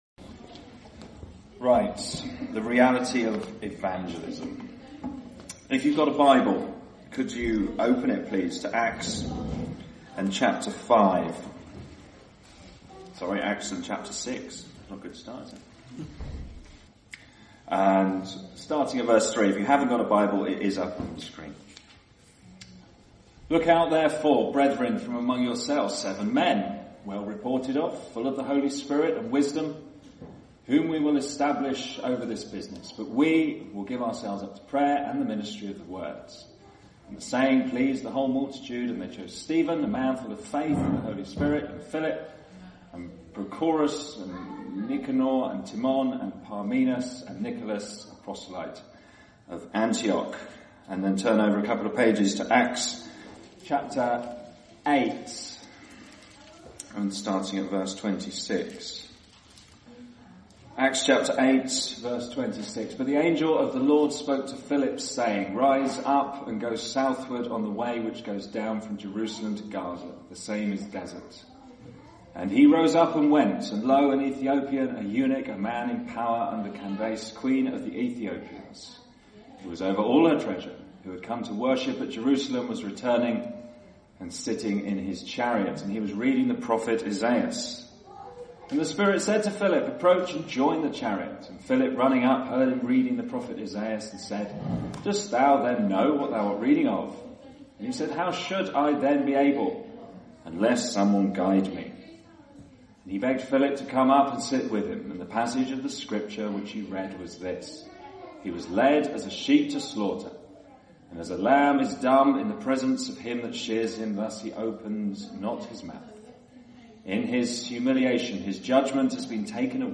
This year at Refresh 2024 we looked at the subject of Reality and what it means to be a real Christian. In this talk, you we will look at the reality of Evangelism. It is essential that we have two things if we desire to have reality in our outreach. Firstly a love for Christ and secondly a love for souls.